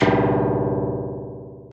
Harpsicord